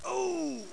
SCREAM5.mp3